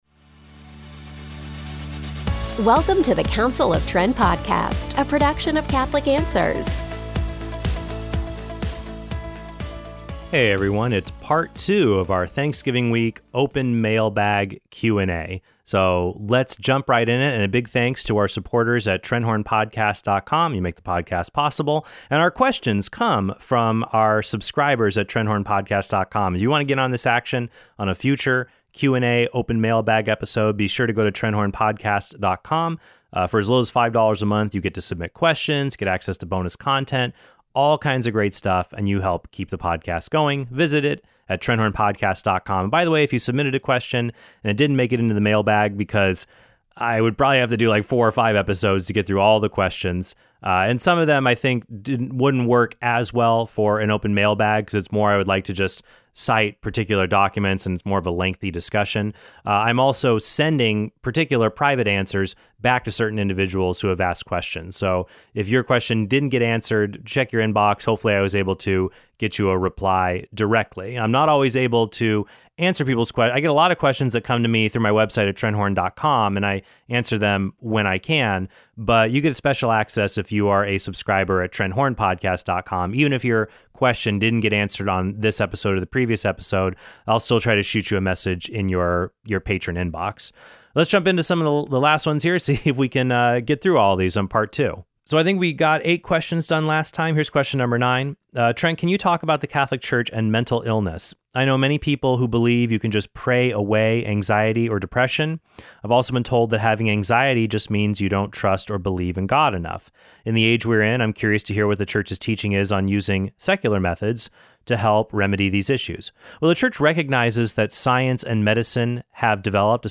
It’s part two of our Thanksgiving week open mailbag Q and A